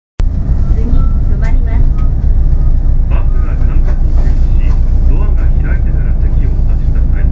音声合成装置  クラリオン(ディスプレイ１)